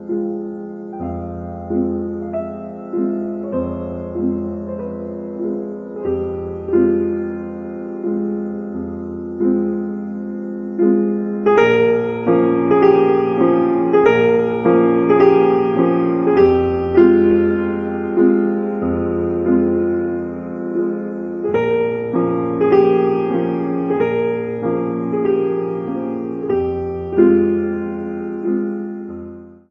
Category: Classical music ringtones